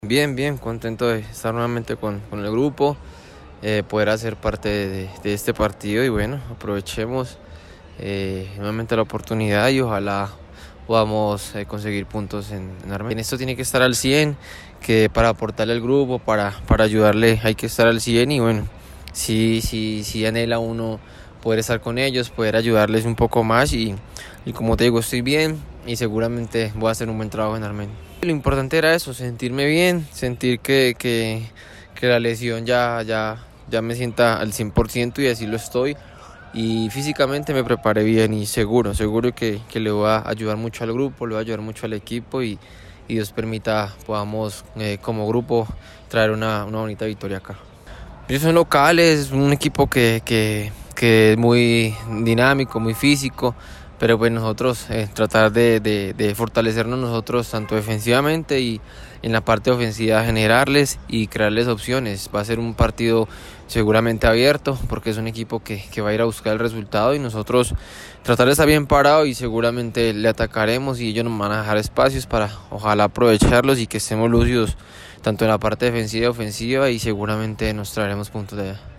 Zona Mixta: